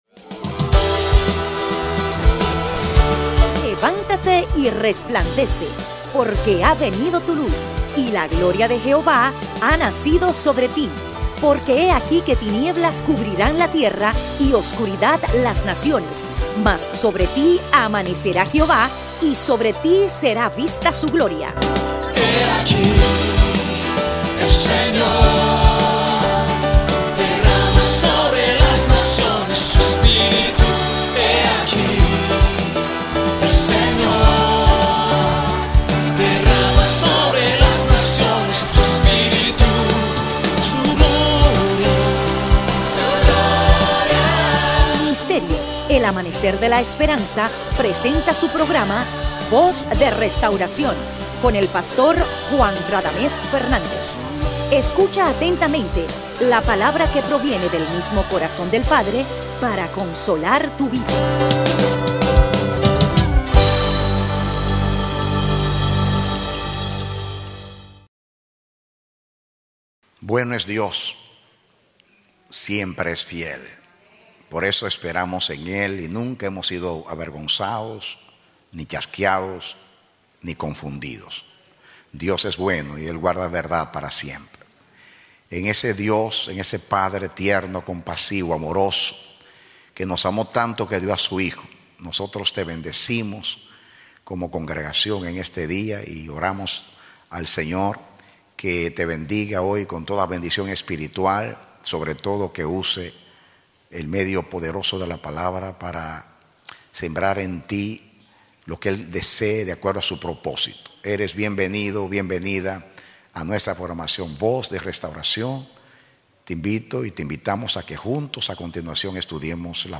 Prédicas – El Amanecer de la Esperanza Ministry